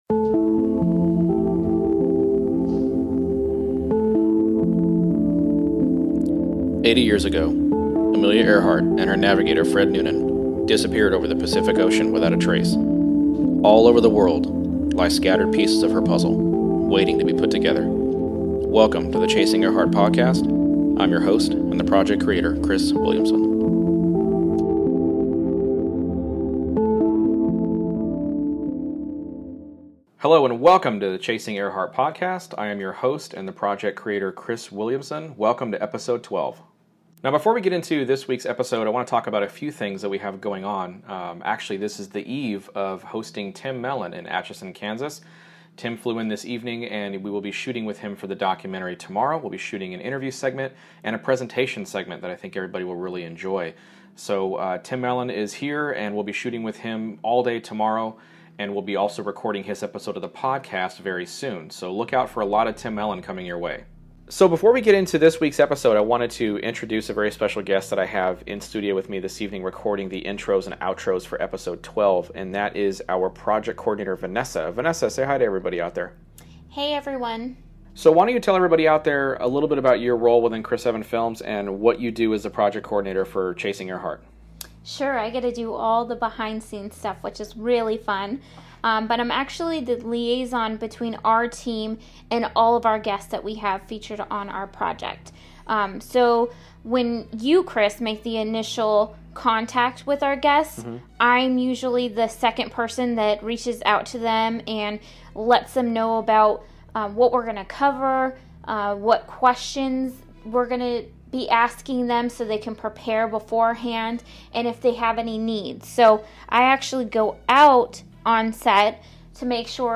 Enjoy this wonderfully engaging and thought provoking interview